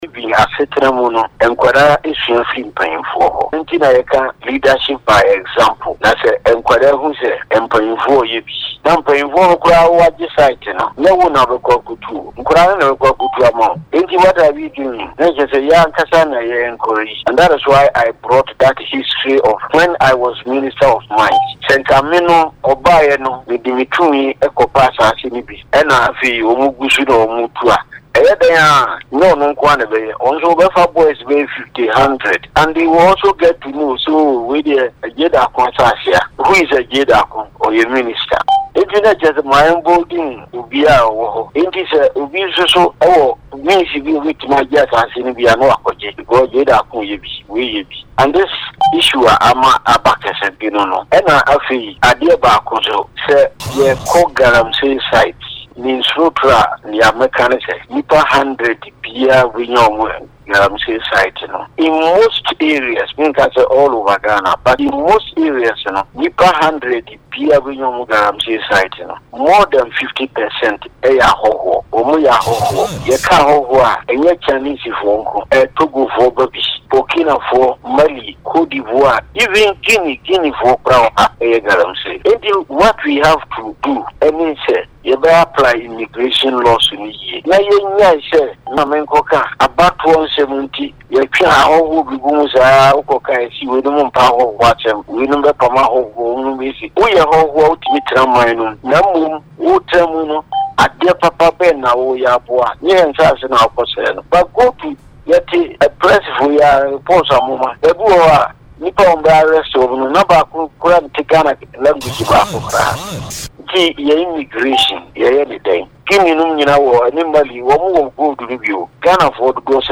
Speaking in a phone interview on Hot FM’s Morning Drive